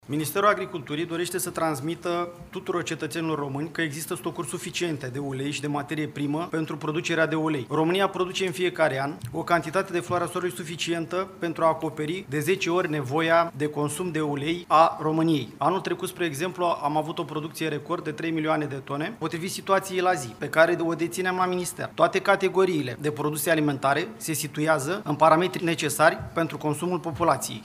Secretarul de stat din ministerul agriculturii – Sorin Moise – spune că anul trecut a fost înregistrată o producție record de floare soarelui, de trei milioane de tone.